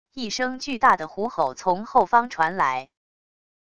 一声巨大的虎吼从后方传来wav音频